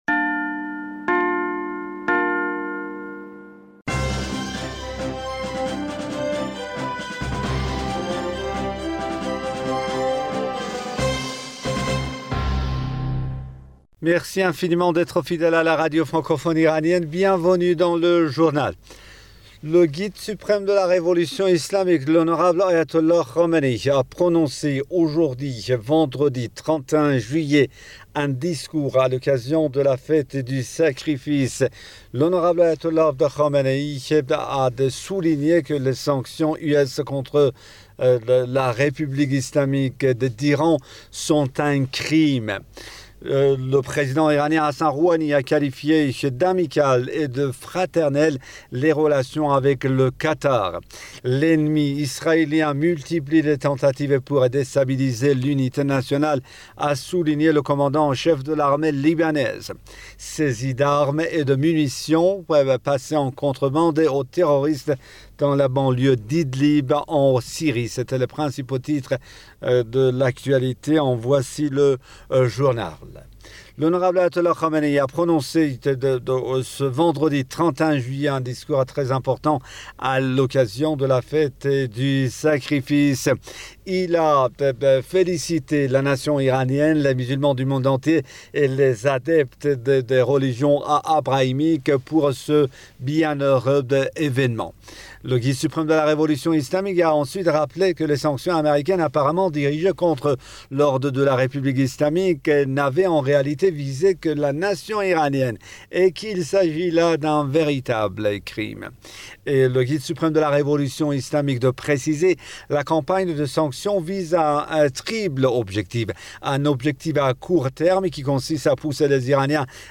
Bulletin d'information du 31 Juillet 2020